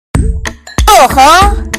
SFX哦吼哦豁音效下载
SFX音效